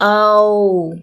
Neither long nor short | about, powder, taoism
– au